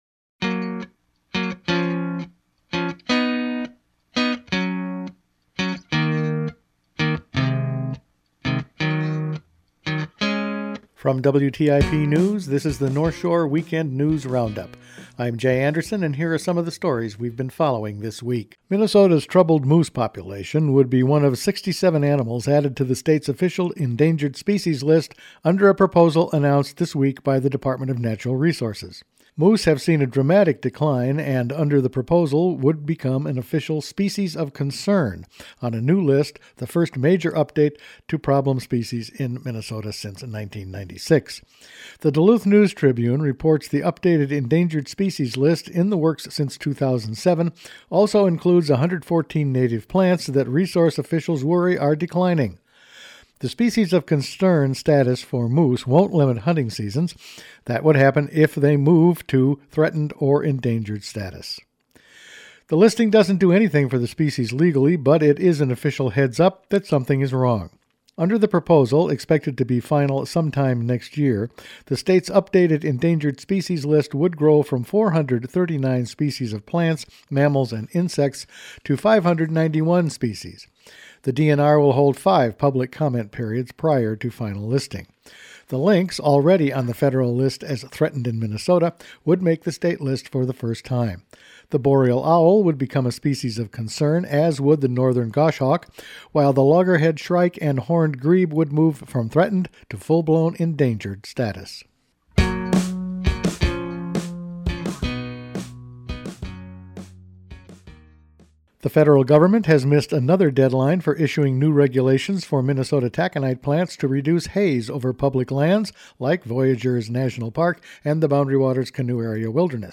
Weekend News Roundup for December 15